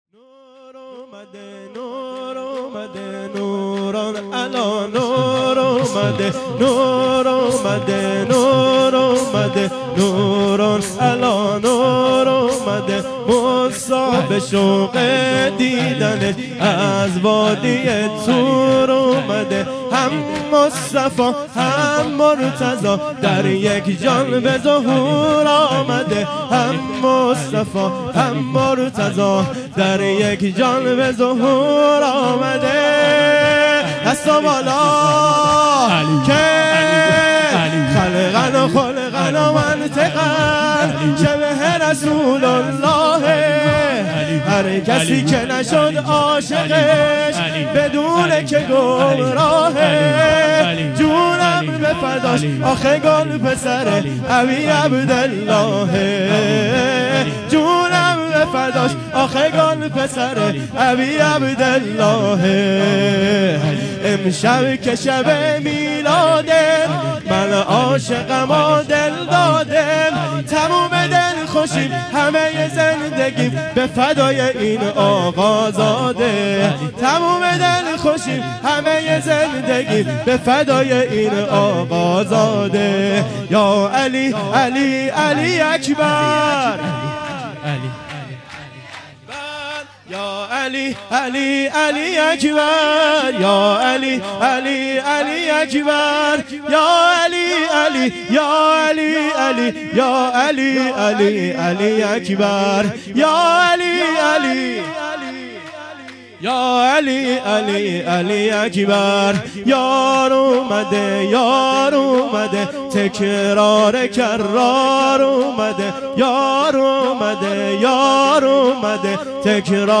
سرود | نور آمده نور آمده| بانوای گرم